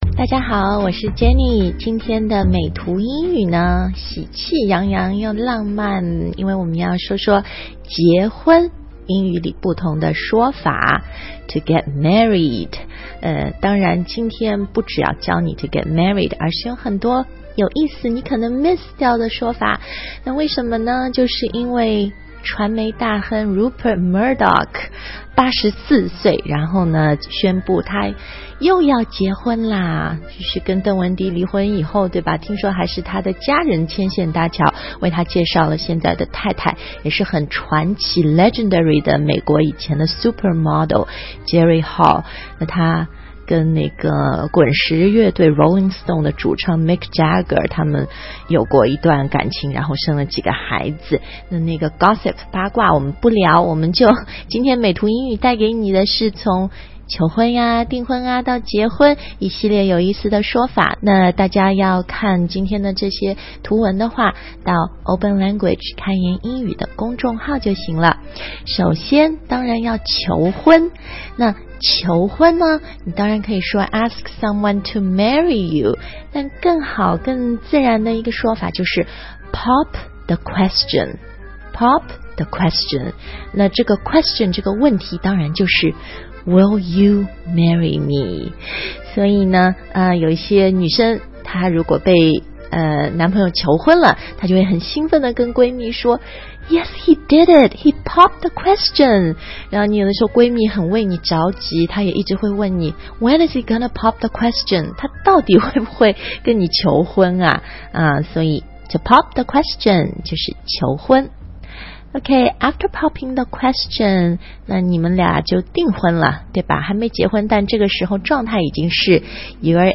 注意aisle的"s" 不发音，这个词的读音是 [ aɪl ]